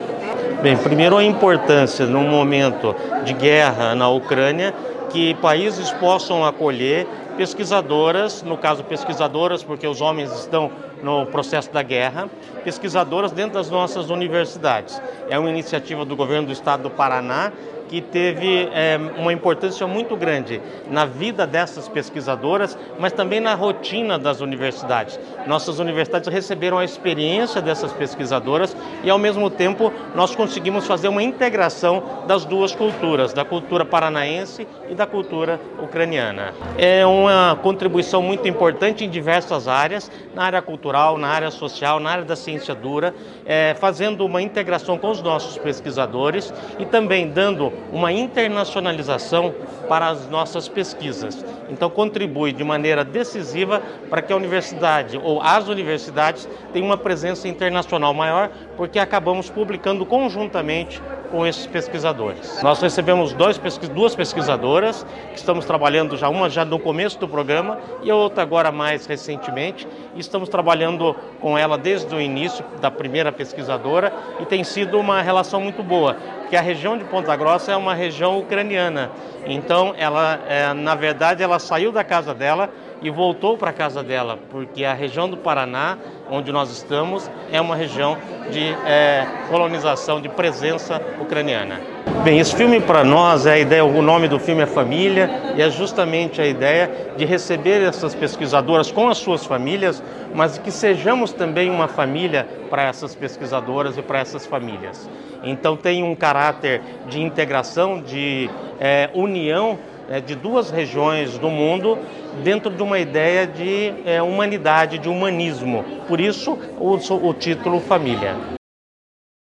Sonora do reitor da UEPG, Miguel Sanches Neto, sobre o documentário que mostra trajetória de cientistas ucranianos acolhidos no Paraná